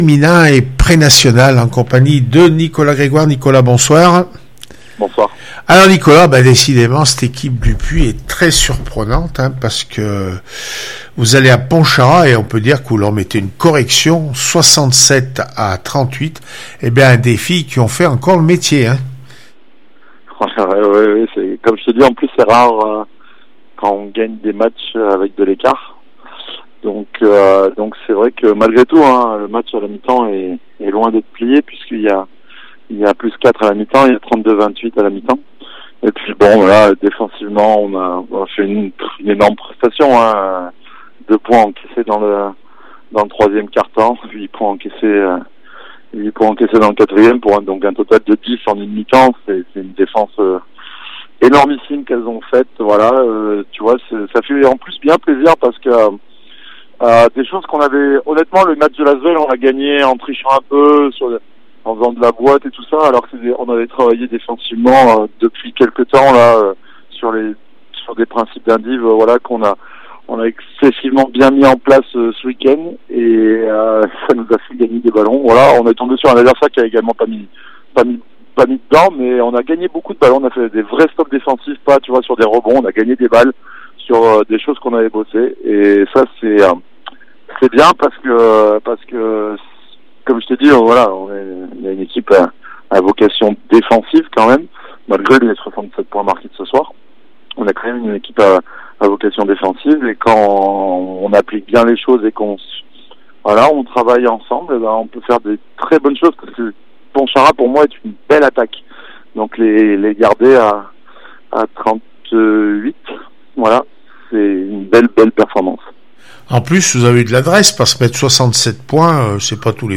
basket prenationale aura féminine pontcharra 38-67 asmb le puy réac après match 191020